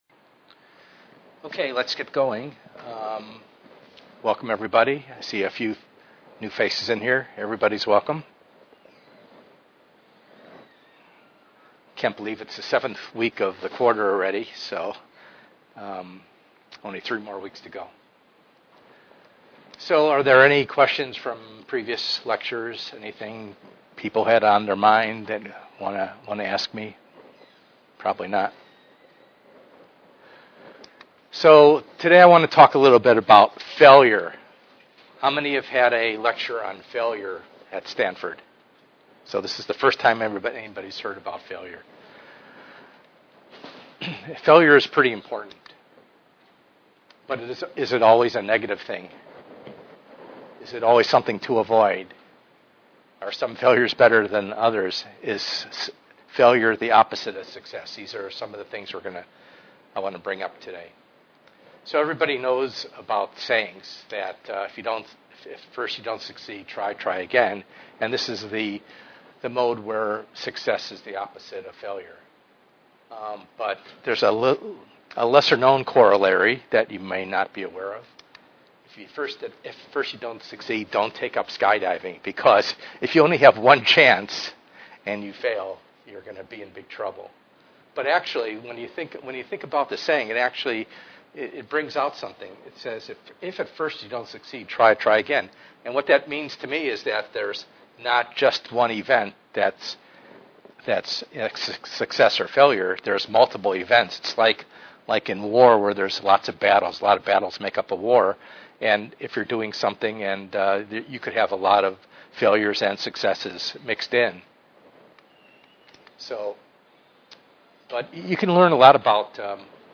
ENGR110/210: Perspectives in Assistive Technology - Lecture 7a